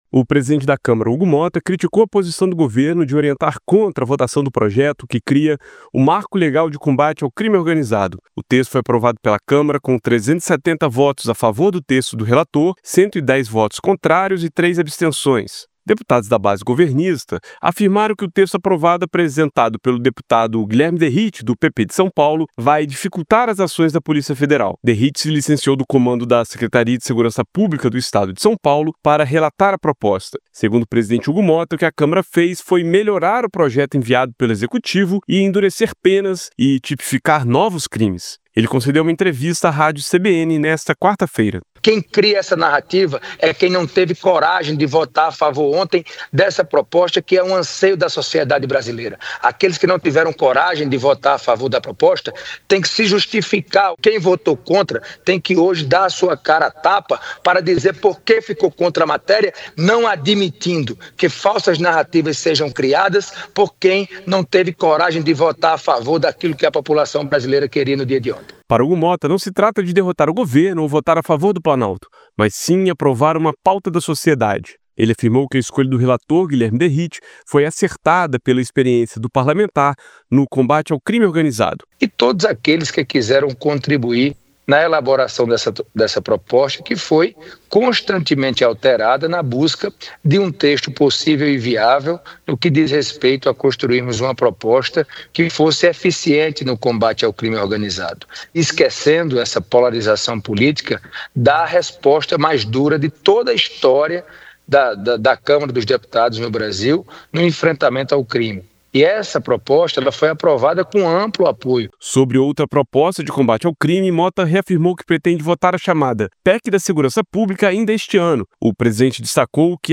PRESIDENTE DA CÂMARA CRITICA GOVERNO POR SE OPOR À VOTAÇÃO DO MARCO LEGAL CONTRA CRIME ORGANIZADO; GOVERNO ALEGA QUE PROPOSTA ENCAMINHADA PELO EXECUTIVO FOI MODIFICADA E PODE DIFICULTAR AÇÕES DA POLÍCIA FEDERAL. A REPORTAGEM